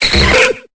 Cri de Métamorph dans Pokémon Épée et Bouclier.